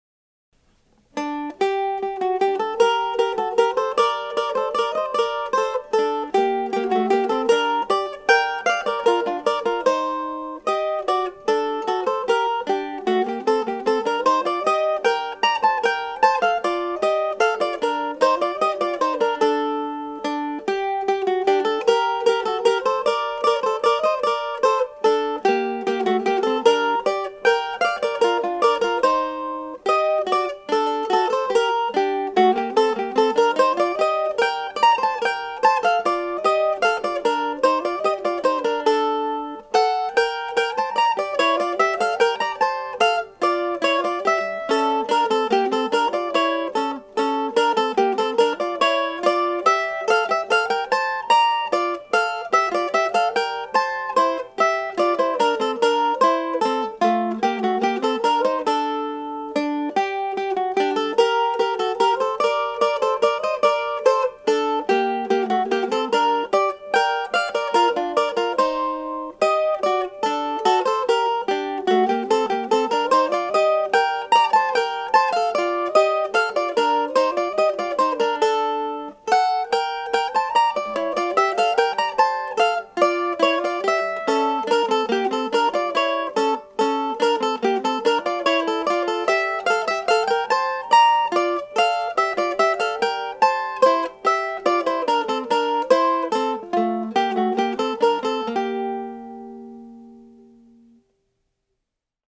"I composed these simple duets for 2 mandolins in early June 2006 as I was completing the work of transcribing the Eighteen Duettinos for Two Guittars; Two French Horns or Two Clarinetts" that were published by William Bates around 1770 in London.
My goal was to keep the music simple (not usually a big problem for me), to limit each piece to one page of paper in my notebook and to have the first mandolin part carry the whole melody with the second part supplying basic harmonies with lots of thirds.